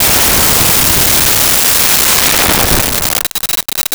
Explosion Auto 1
Explosion Auto_1.wav